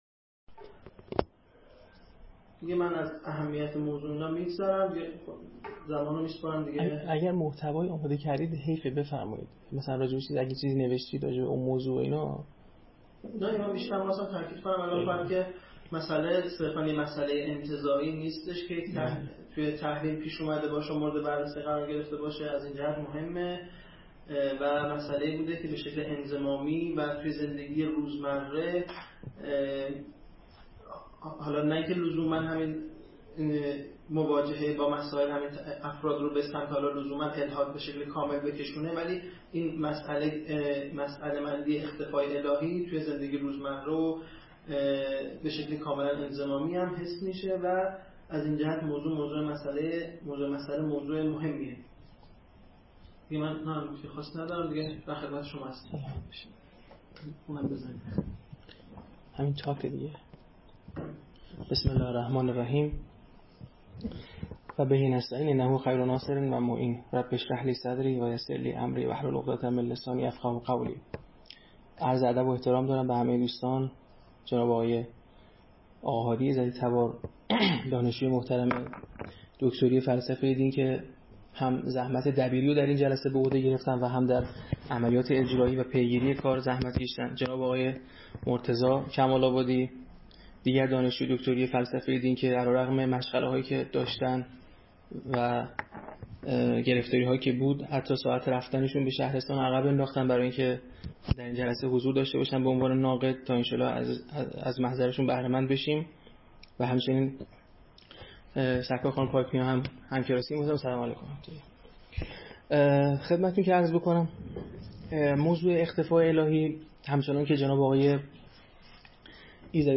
به همت انجمن علمی الحاد مدرن دانشگاه باقرالعلوم (ع)